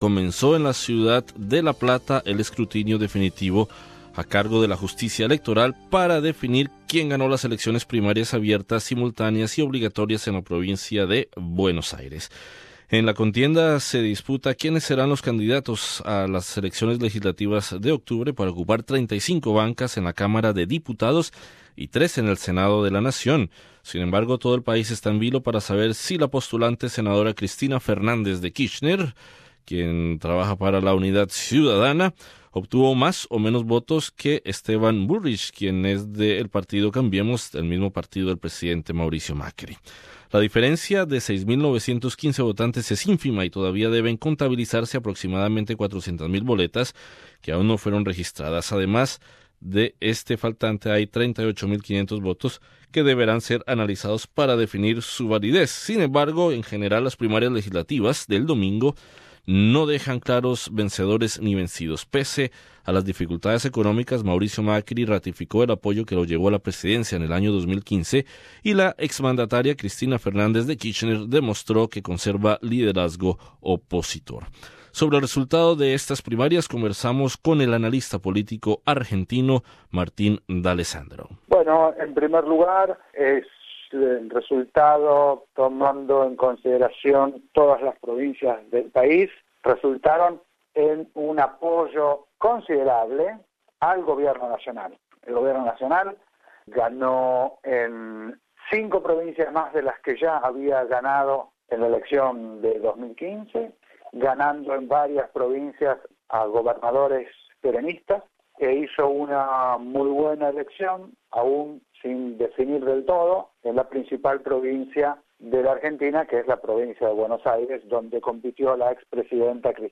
Entrevista con el analista político argentino